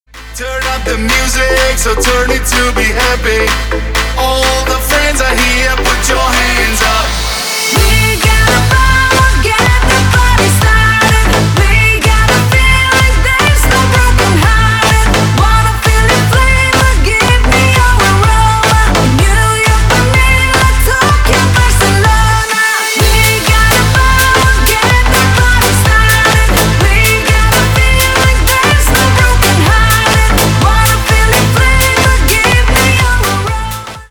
Танцевальные
клубные # громкие